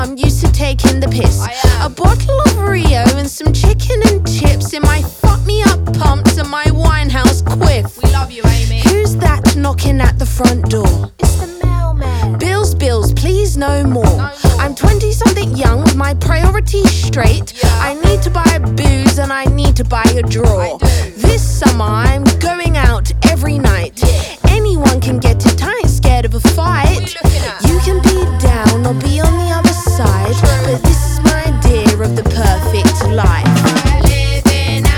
Hip-Hop Hip-Hop Rap
Жанр: Хип-Хоп / Рэп